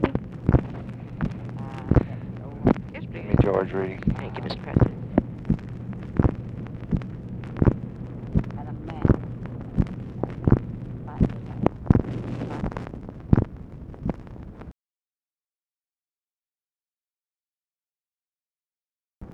LBJ ASKS TELEPHONE OPERATOR TO PLACE CALL TO GEORGE REEDY